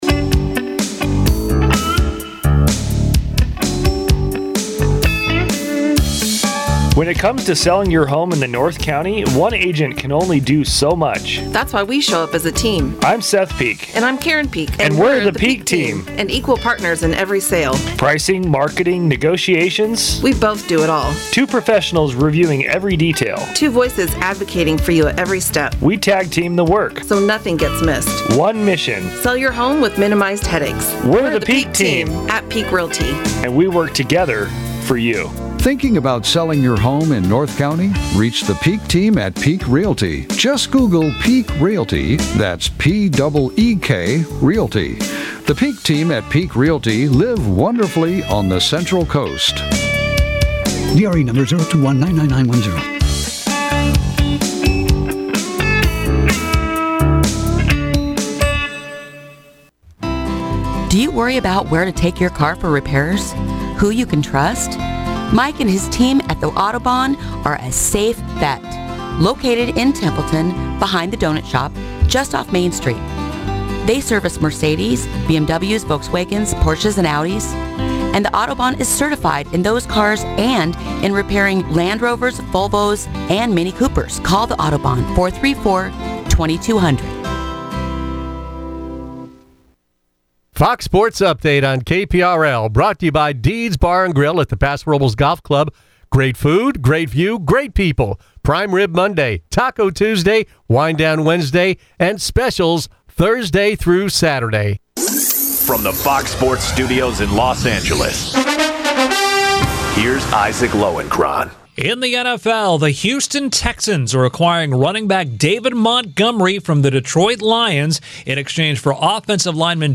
Tune in to the longest running talk show on the Central Coast.